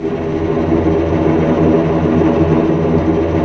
Index of /90_sSampleCDs/Roland LCDP08 Symphony Orchestra/STR_Vcs Bow FX/STR_Vcs Trem wh%